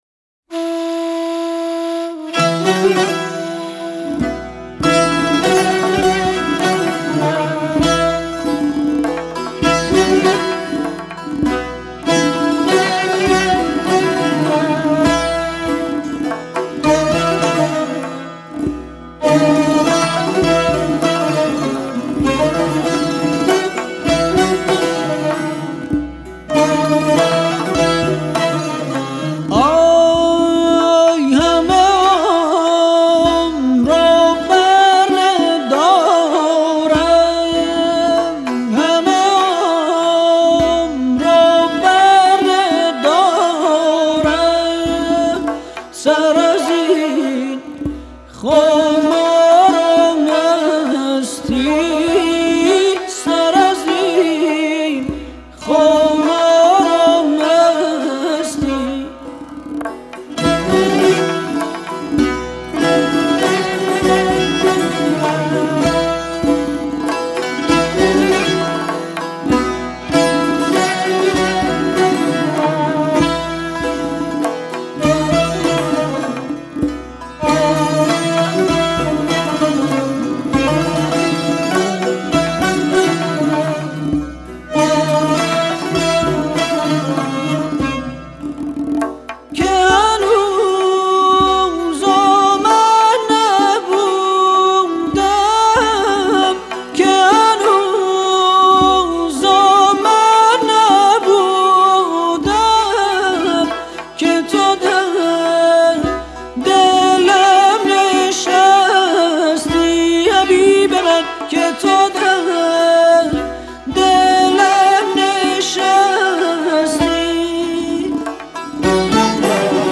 در مایۀ بیات‌اصفهان
کمانچه
تار و سه تار
عود
سنتور
تمبک